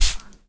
spray_bottle_01.wav